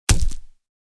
Hit_Enemy.wav